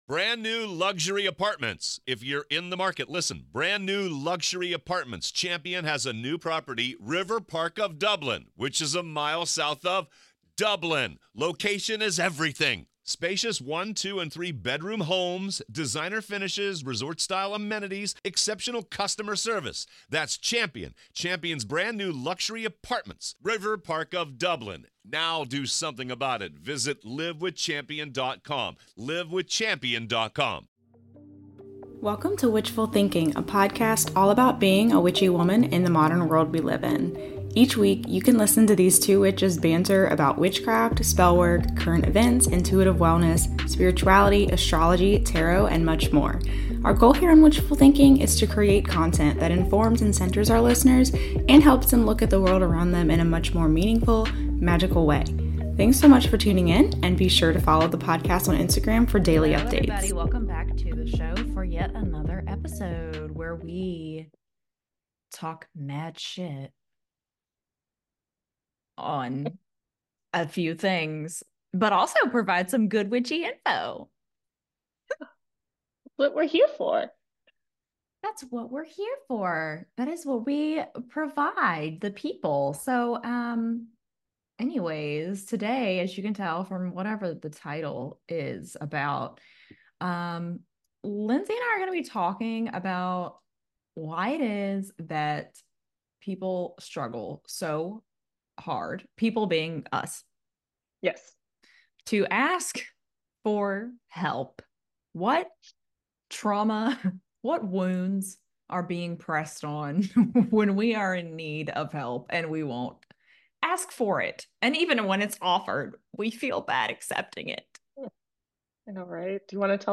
Why is it so damn hard to suck it up and just ask for help when you need it?! Tune in for a conversation about: